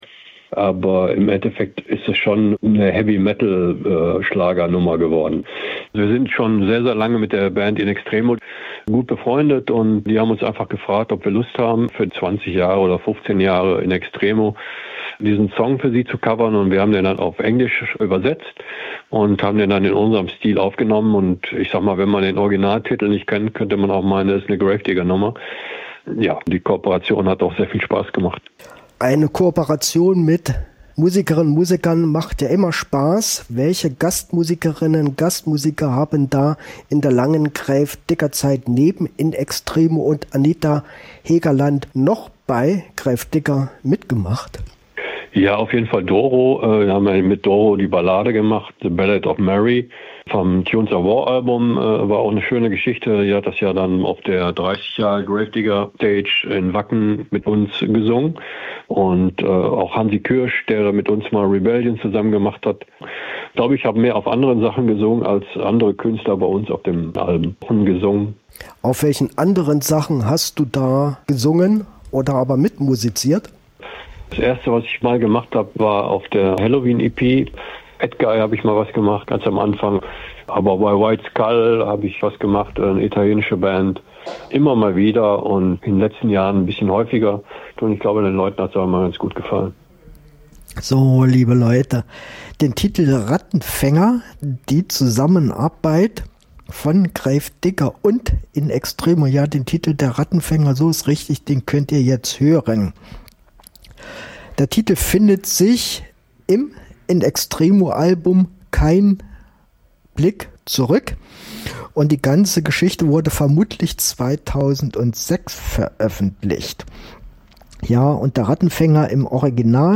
Musik von Rock bis Metal!